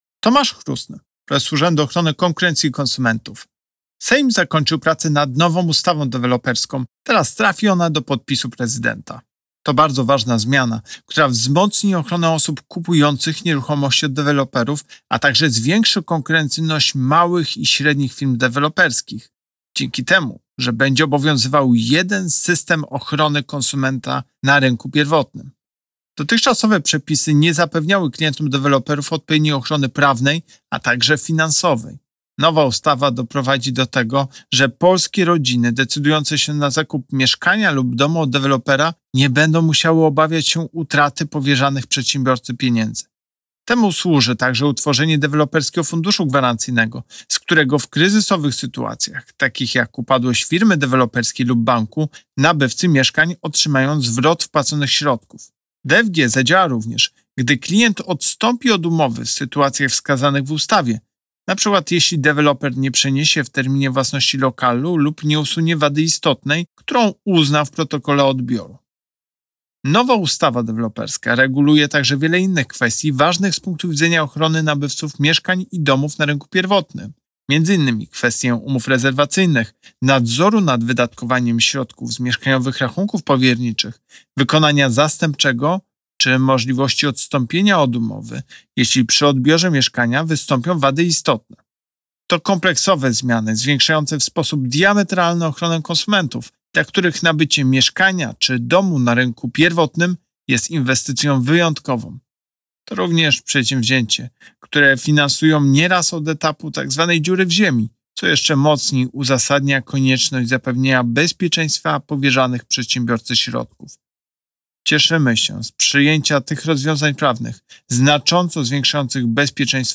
Pobierz wypowiedź Prezesa UOKiK Tomasza Chróstnego Mieszkaniowe rachunki powiernicze i DFG Obecnie deweloperzy muszą gromadzić wpłaty klientów na mieszkaniowych rachunkach powierniczych (MRP) .